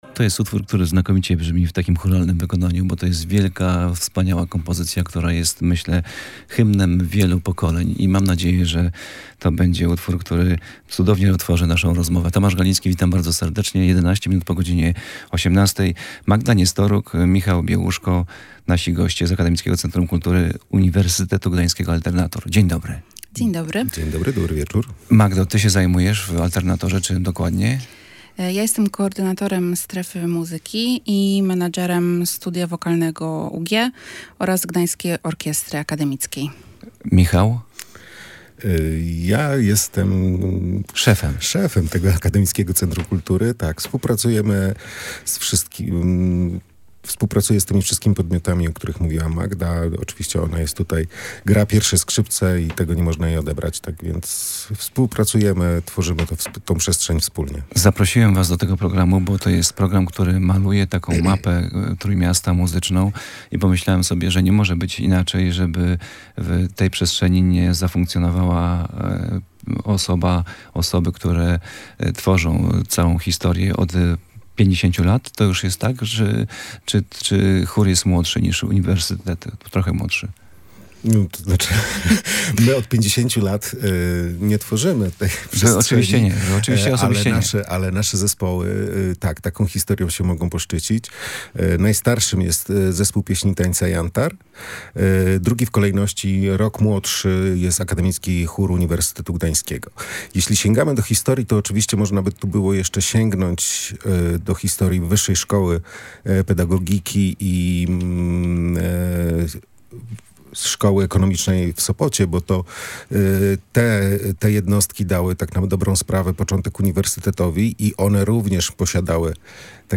W rozmowie goście opowiedzieli zarówno o bogatej historii i współczesnej działalności „Alternatora”, jak i o tym, w jaki sposób centrum kultury integruje społeczność akademicką oraz promuje kulturę w całym Trójmieście.